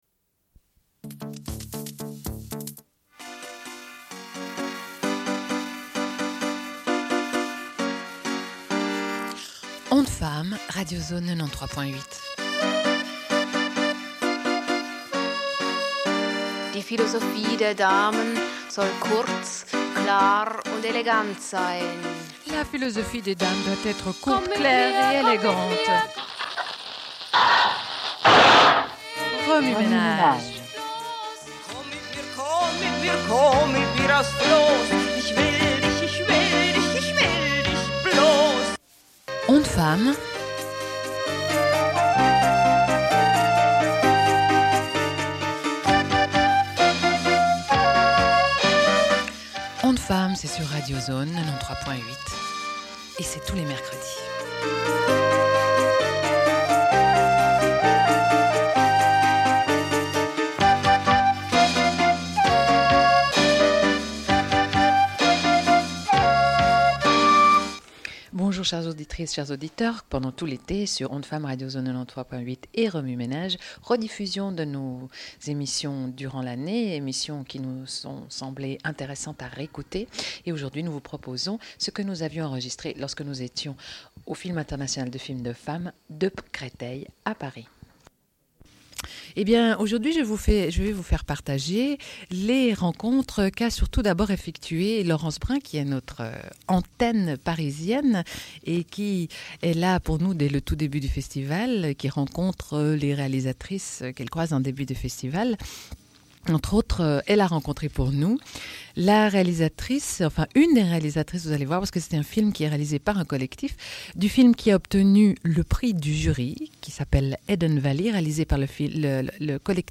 Une cassette audio, face A31:05